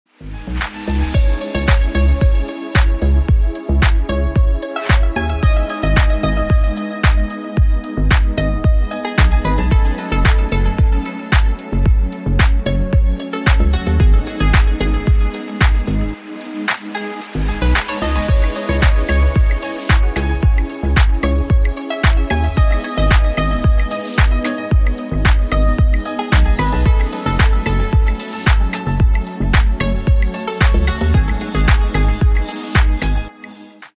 Клубные Рингтоны » # Рингтоны Без Слов
Танцевальные Рингтоны